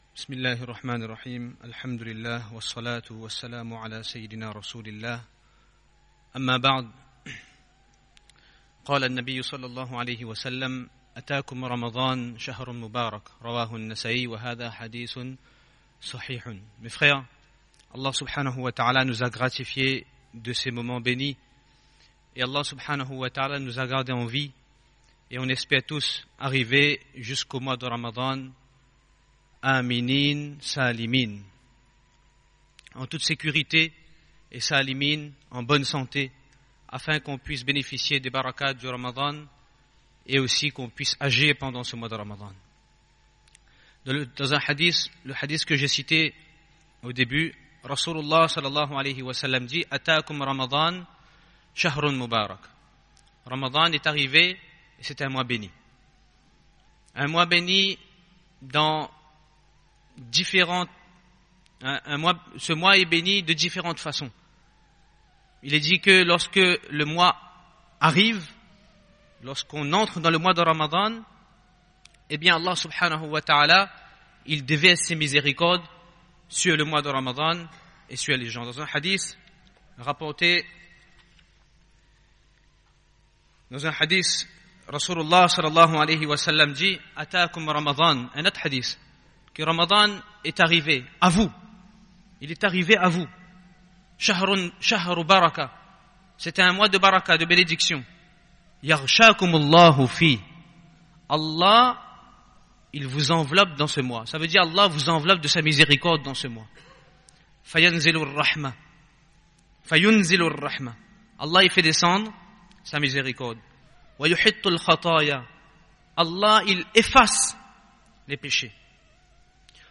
Discours Djoum'ah, Atyaboul Massadjid St Pierre